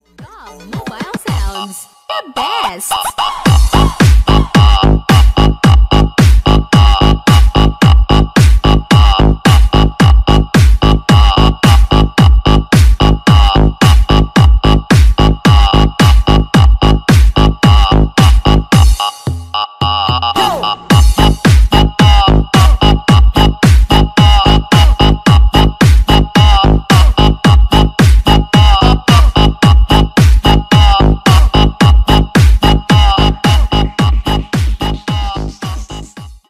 • Качество: 320, Stereo
громкие
зажигательные
веселые
EDM
Melbourne Bounce
долбящие
Веселая музыка из Тик Тока